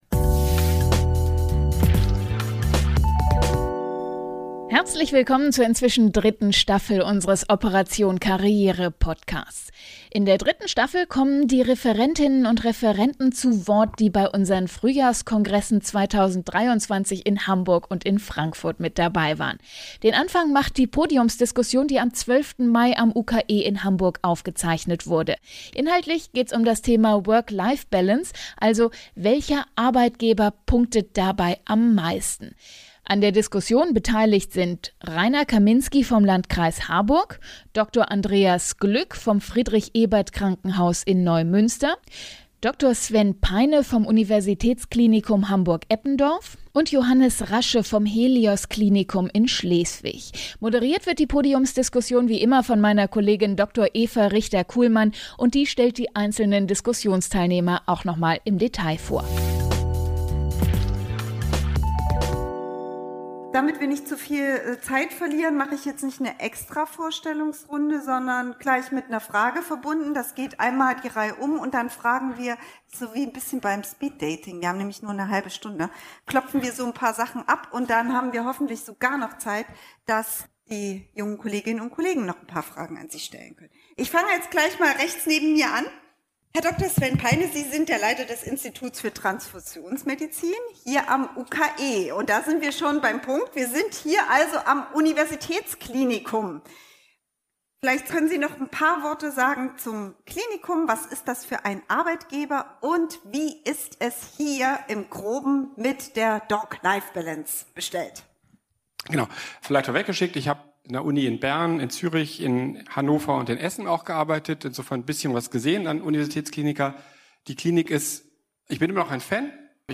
Uniklinik, kommunales Krankenhaus oder Niederlassung: Wo gibt es die beste Doc-Life-Balance für Ärztinnen und Ärzte? Bei der Podiumsdiskussion auf dem Operation Karriere-Kongress in Hamburg am 12. Mai 2023 ging es um dieses Thema.